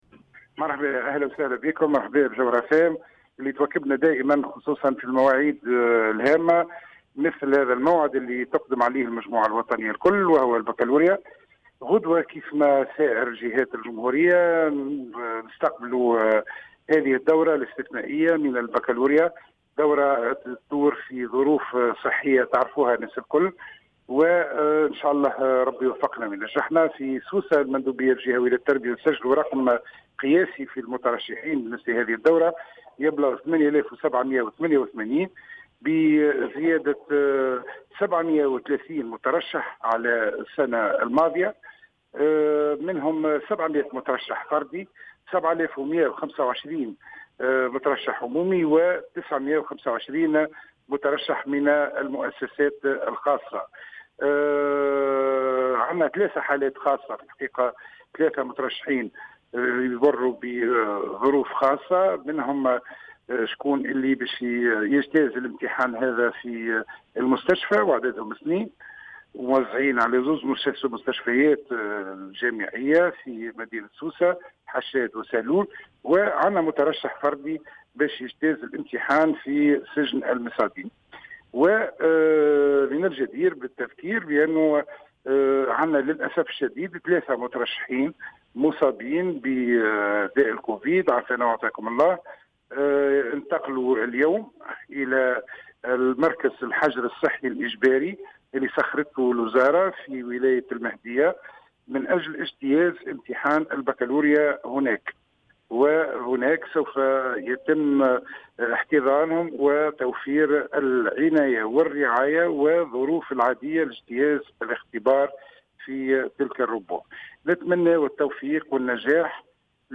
أكد المندوب الجهوي للتربية بسوسة، نجيب الزبيدي، في تصريح للجوهرة أف أم، اليوم الثلاثاء، أن ولاية سوسة تسجل هذه السنة رقما قياسيا في عدد المترشحين للدورة الرئيسية للبكالوريا، بلغ 8788 مترشحا، بزيادة 730 مترشحا مقارنة بالسنة الماضية.